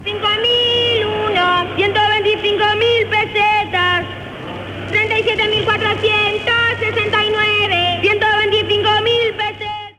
Transmissió del sorteig de la Rifa de Nadal.
Informatiu